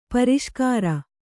♪ pariṣkāra